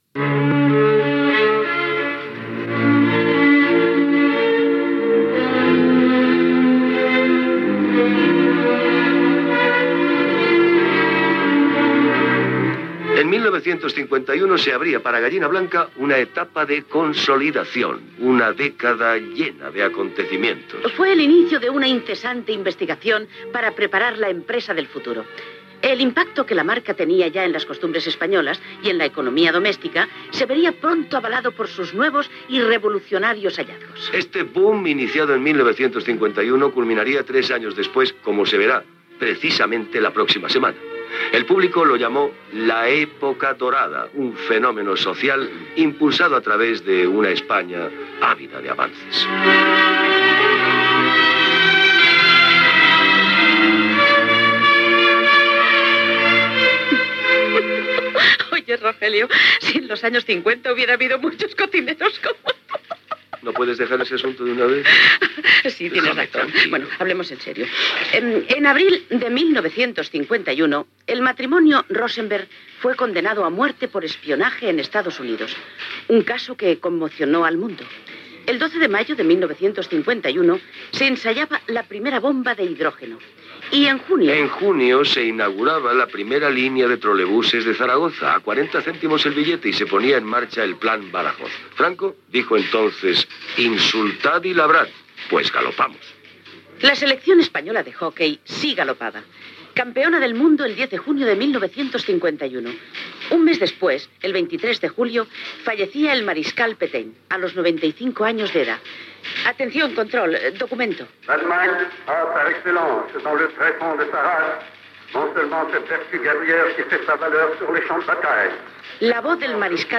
Gallina Blanca, fragment del programa especial amb motiu dels 50 anys de l'empresa. Fets significatius de l'any 1951.